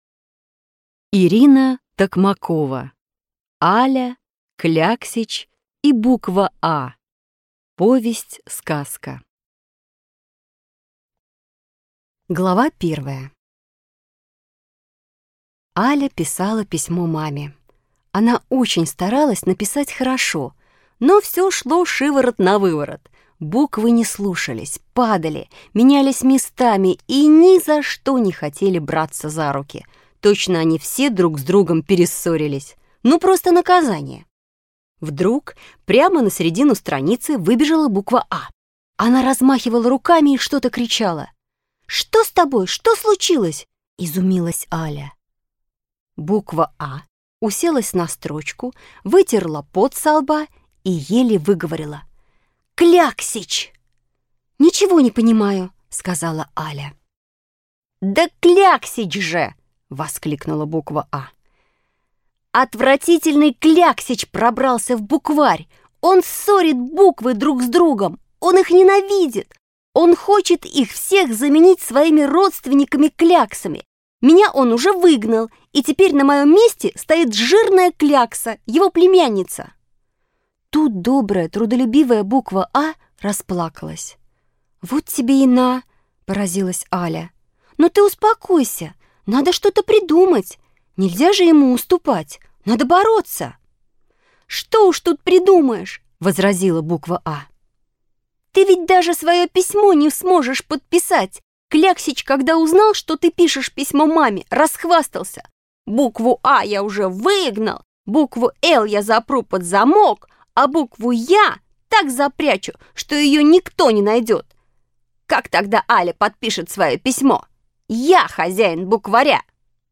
Аудиокнига Аля, кляксич и буква «А» | Библиотека аудиокниг